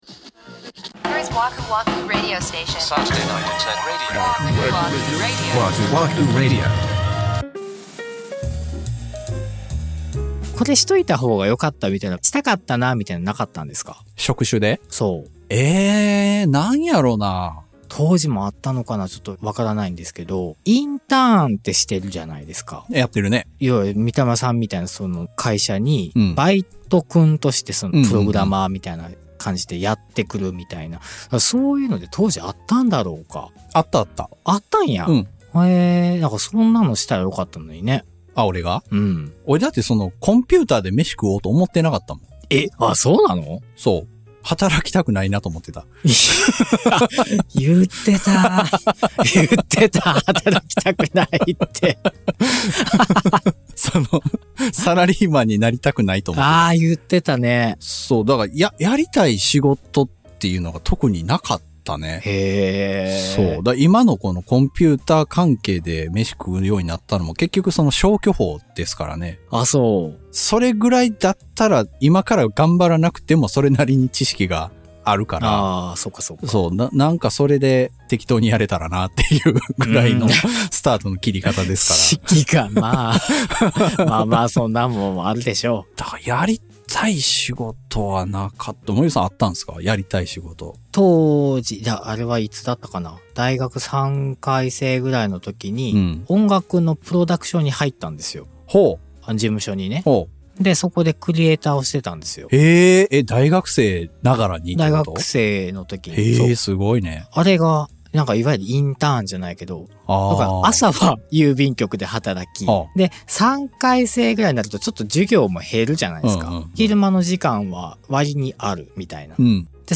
日常観察家事情報人生共感型 ジャスト10分バラエティ。 シュッとしたおっちゃんになりきれない、 宙ぶらり世代の２人が、 関西からお送りしています。